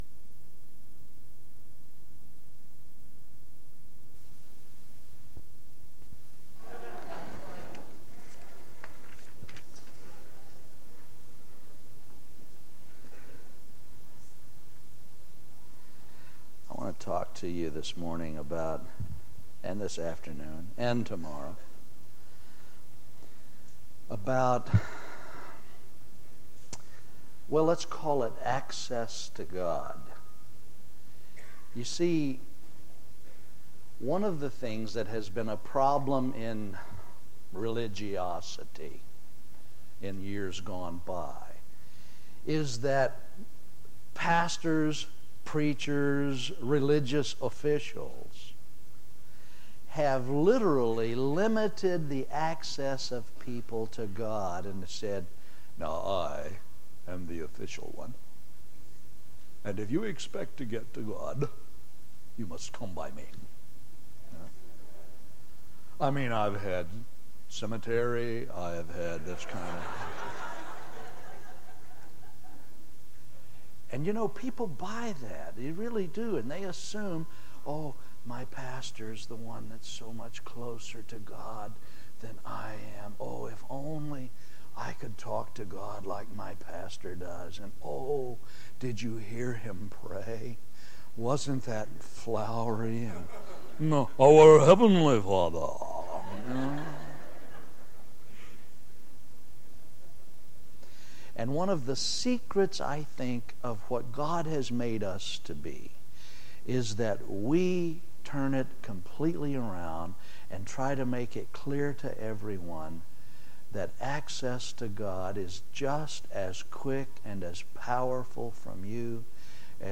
Home » Sermons » 1998 DSPC: Session 7